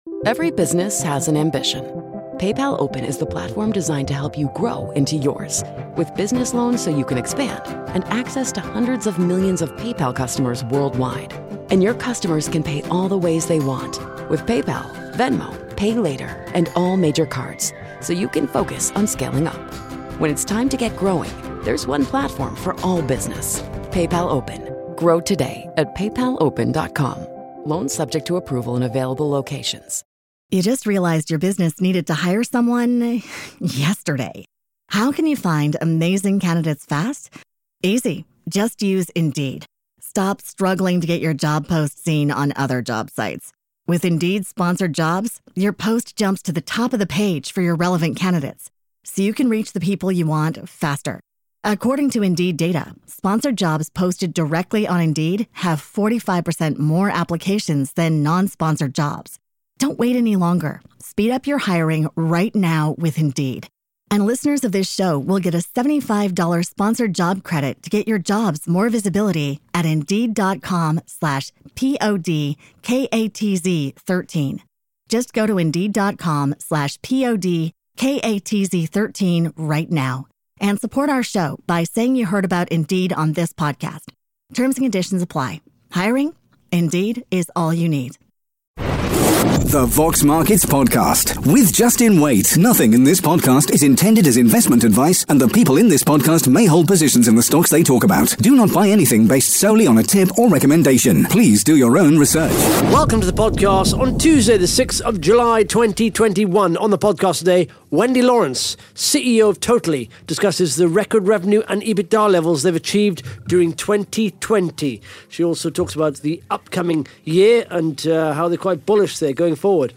(Interview starts at 13 minutes 49 seconds) Plus the Top 5 Most Followed Companies & the Top 5 Most read RNS’s on Vox Markets in the last 24 hours.